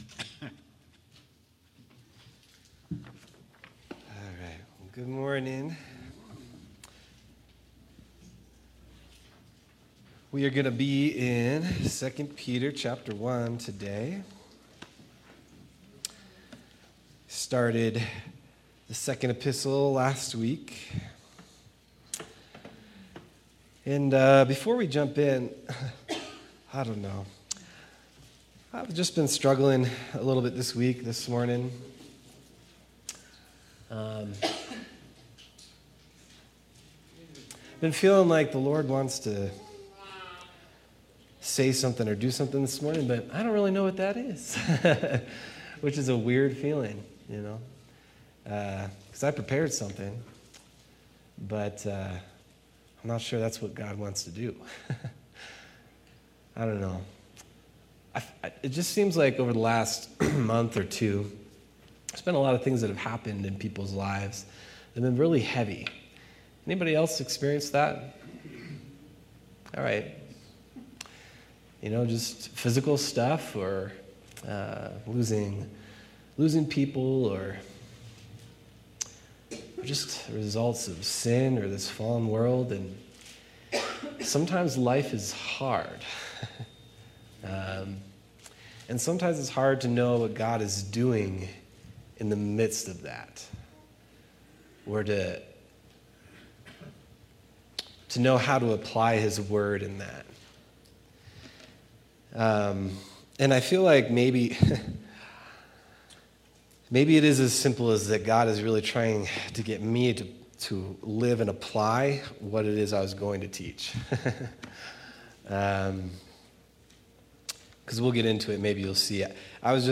September 7th, 2025 Sermon